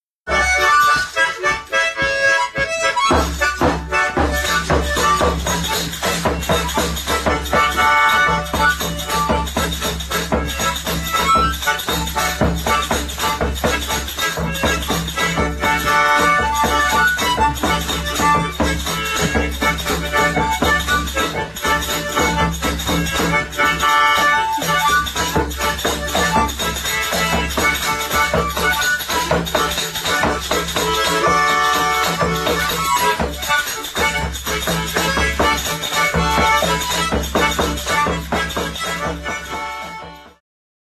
Oberek
organki
nagr. Konstantów, 2004
bębenek